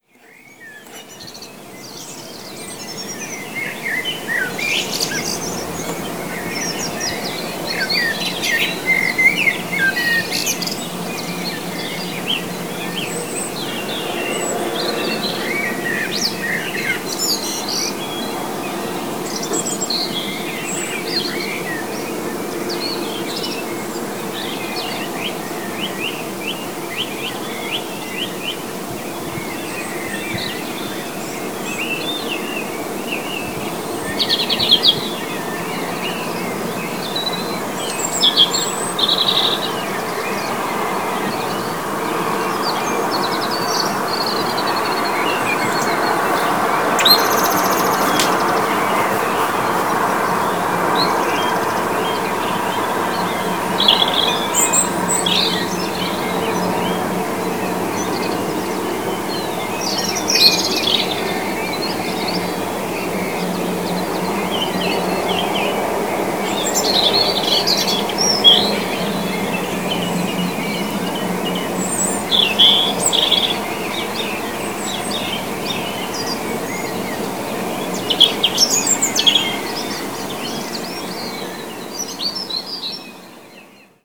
On top of this, I have also been noticing the dawn chorus of birds gradually becoming louder as the winter wanes.
Right now, at the end of February, the dawn chorus is short, a bit muted – there aren’t many birds joining in – and it sound like the equivalent of a orchestra tuning up.
The Dawn Chorus on a February Morning
Dawn-Chorus-Feb.mp3